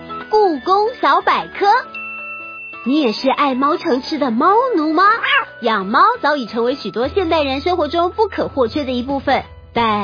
女配音員
活潑輕快旁白
活潑輕快旁白.mp3